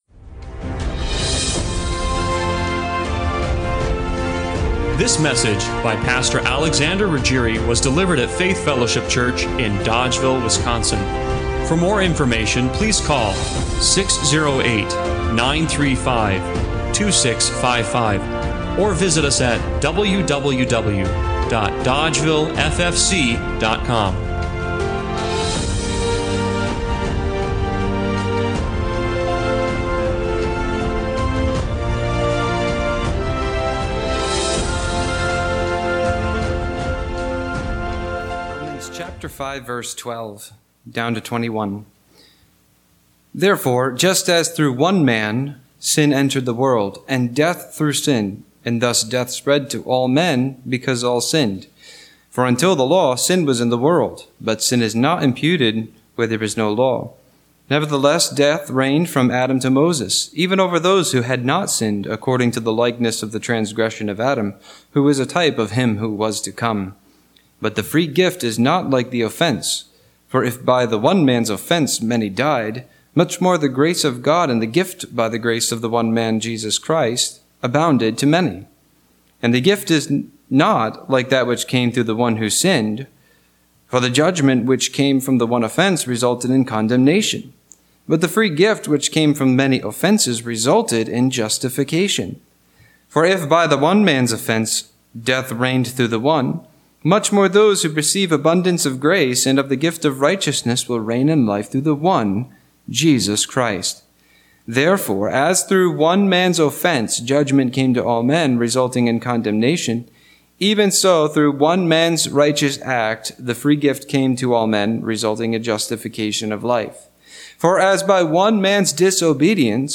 Romans 5:12-21 Service Type: Sunday Morning Worship We've heard so many people talk about "toxic masculinity".